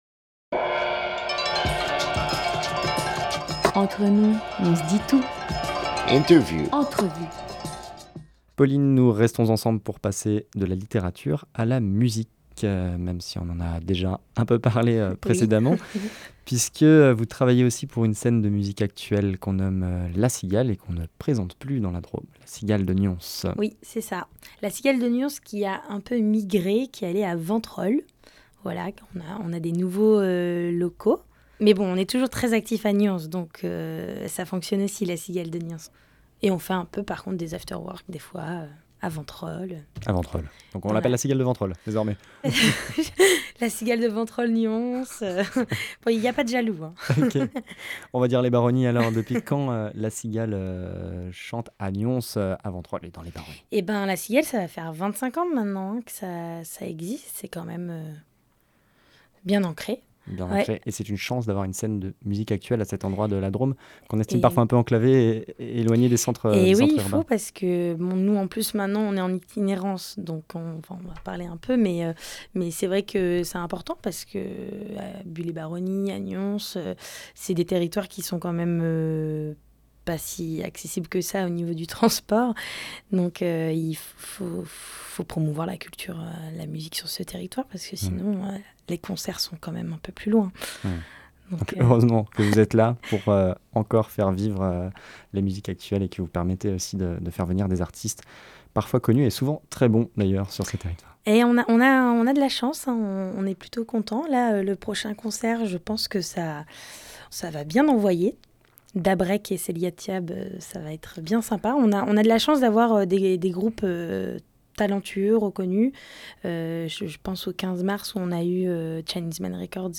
26 septembre 2019 11:49 | Interview